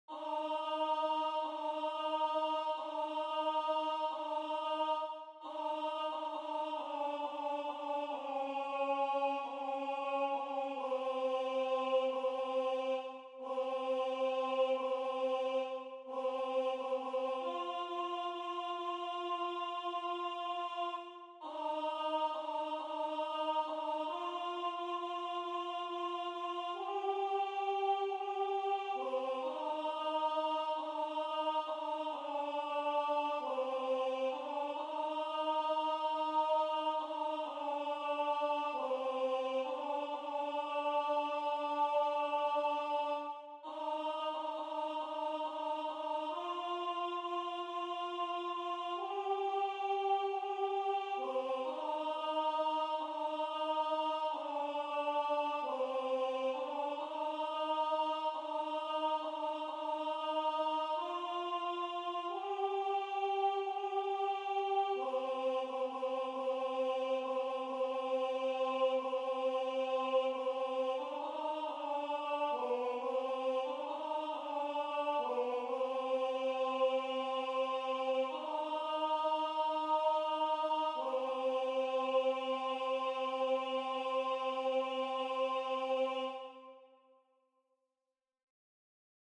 Rendu voix synth.
Alto